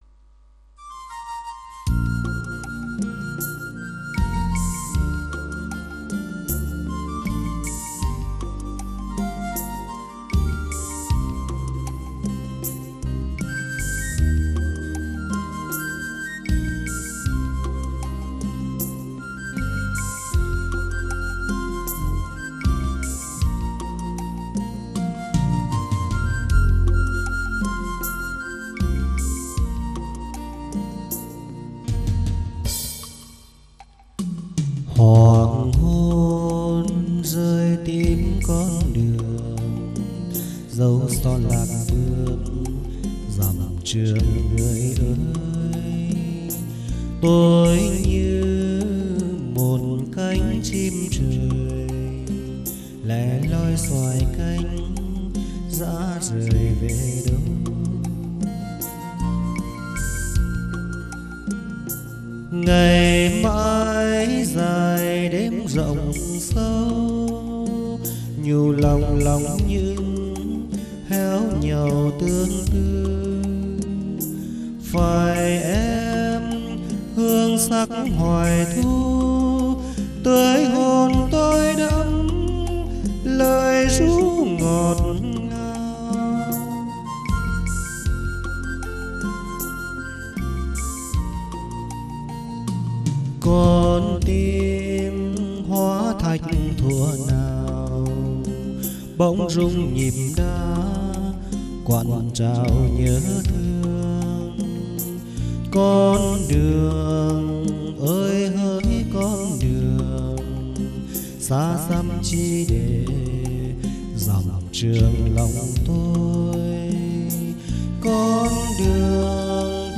(Tải file nhạc Nam: